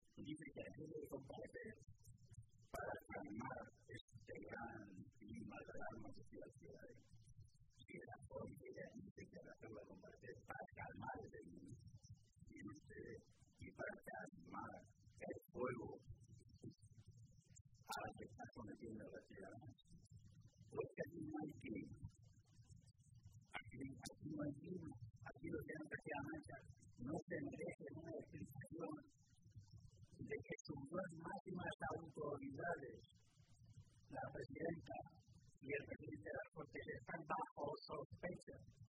Jesús Fernández Vaquero, Secretario de Organización del PSCM-PSOE
Cortes de audio de la rueda de prensa